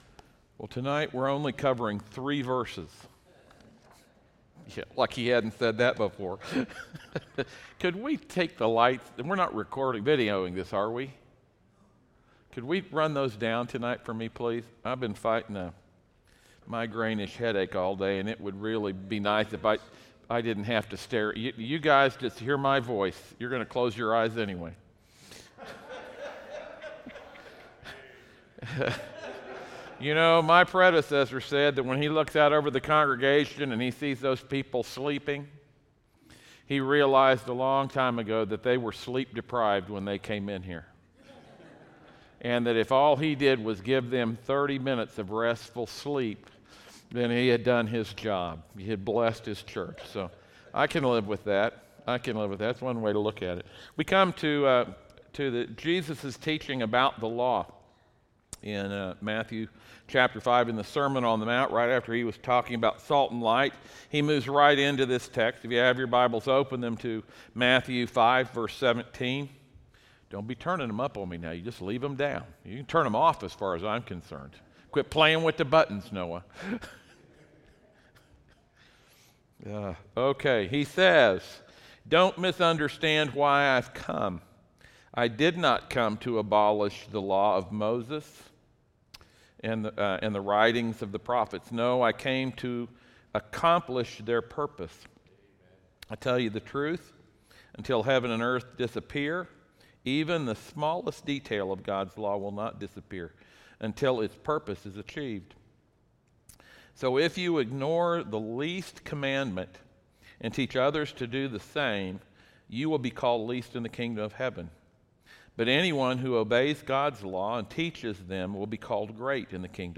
15 Chapters that Shape Everything Passage: Matthew 5:17-20 Service Type: audio sermons « I AM